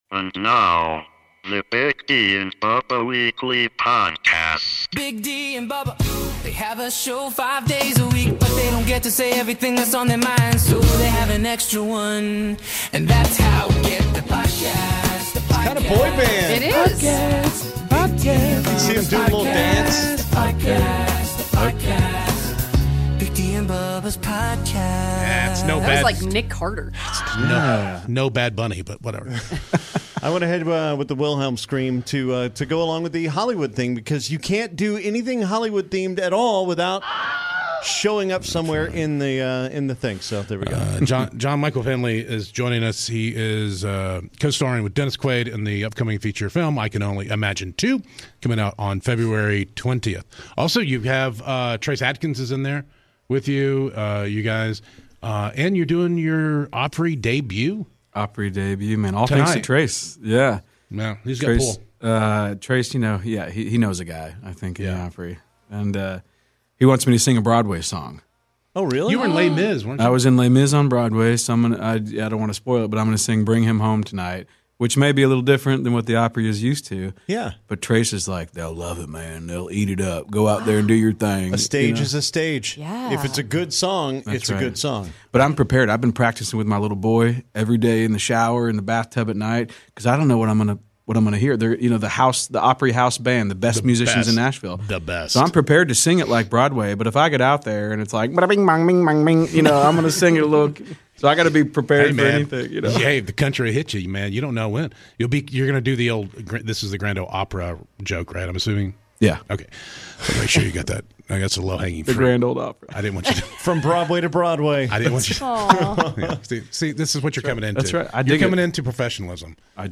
Weekly Podcast #548 - The crew meets and chats with actor and Broadway performer